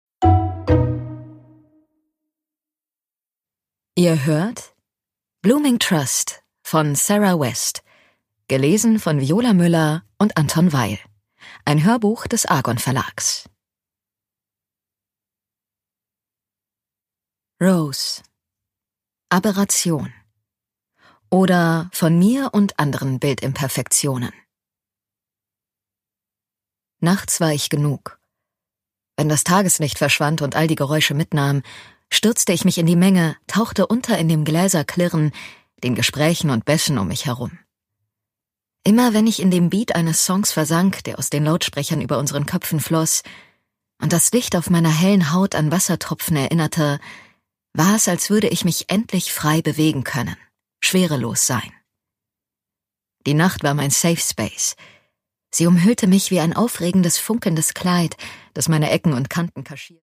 Sara West: Blooming Trust - Rose Garden, Band 2 (Ungekürzte Lesung)
Produkttyp: Hörbuch-Download